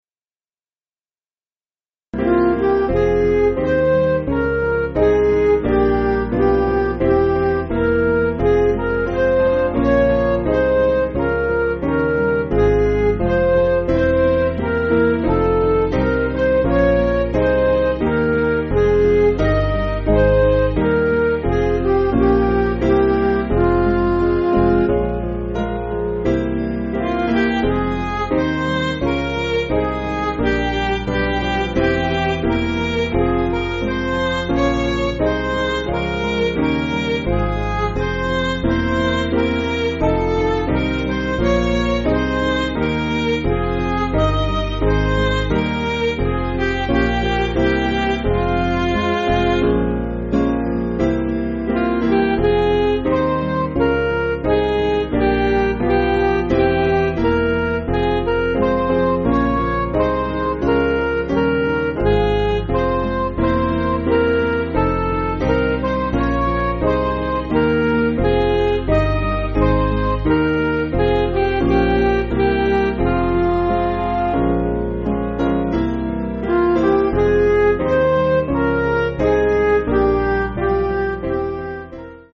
Piano & Instrumental
(CM)   4/Fm